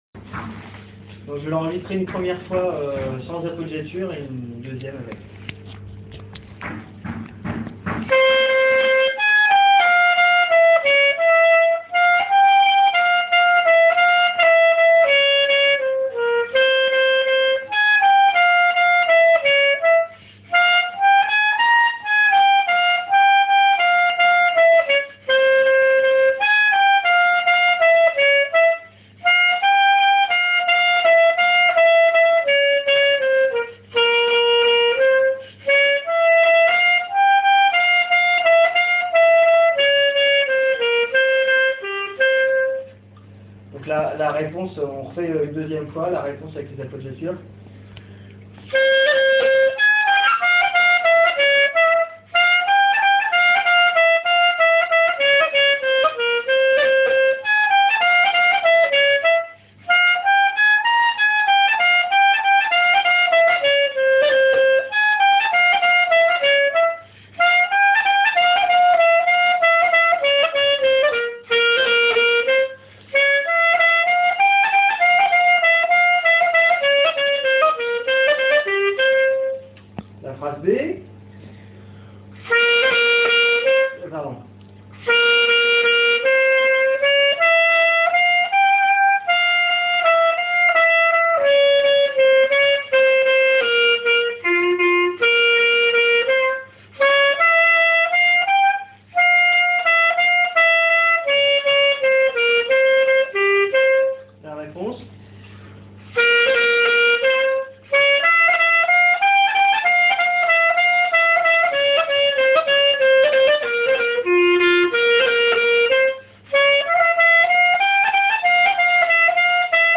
clarinette-bulgare.mp3